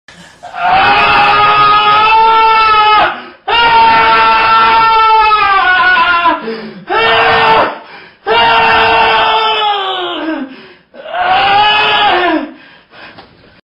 Classic Rage Scream
classic-rage-scream.mp3